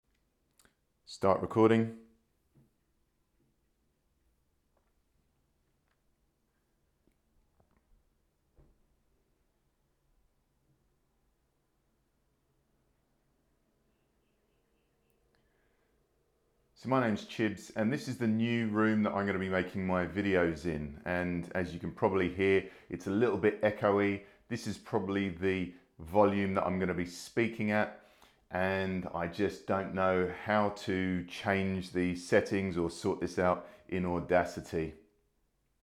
What can I do to reduce the echo on my recording?
Hi guys, I have just moved into a new apartment and the room that I’m using to record my videos is super-echoey. It has a hard floor, concrete walls and ceilings. It’s pretty small about 3 meters by 2.2 meters.
I’m using a rode lav mic, but I do have a rode NT-USB mic that I could potentially use.